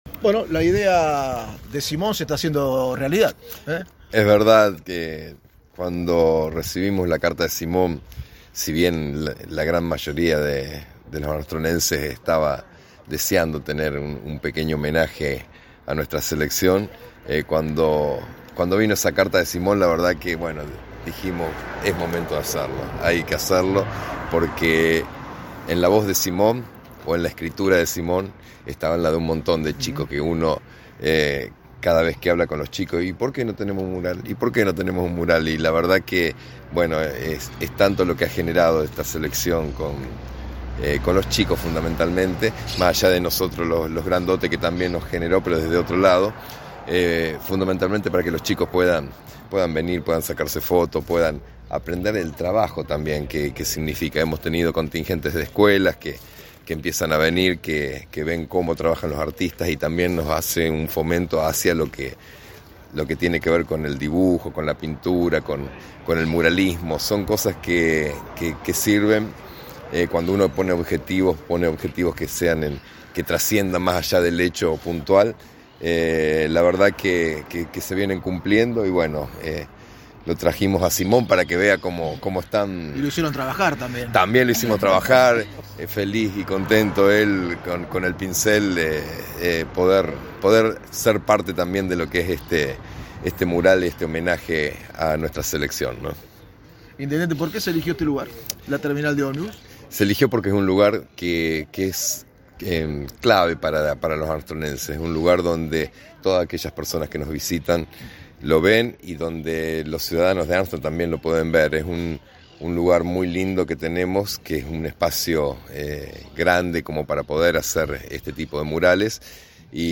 Nota con Intendente Verdecchia